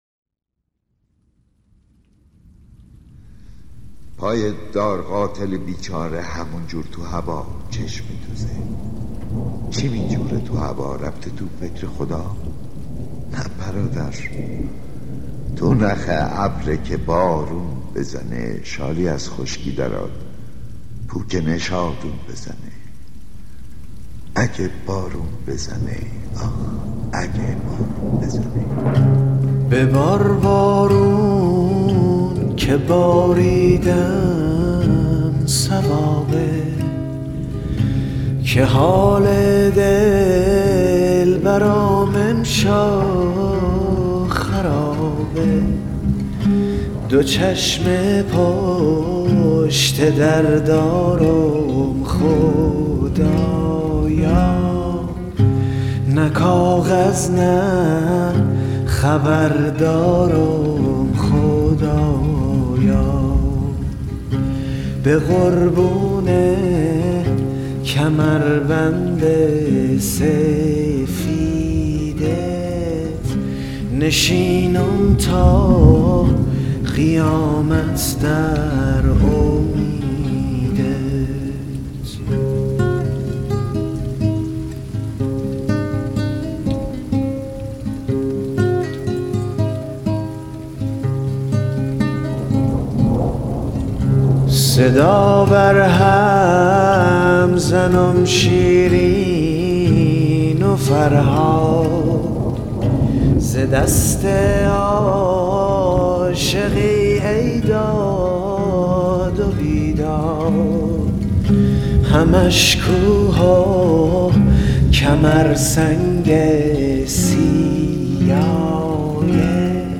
دکلمه
میکس شعر mashup